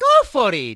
Mario encourages the hesitant wiki editor. From Mario Golf: Toadstool Tour.
Mario_(Go_for_it!)_-_Mario_Golf_Toadstool_Tour.oga